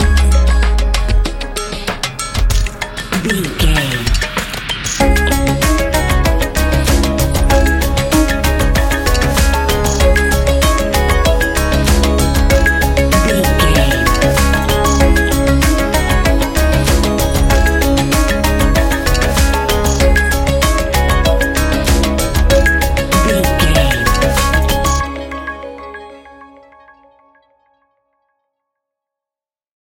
Ionian/Major
C♭
techno
trance
synths
synthwave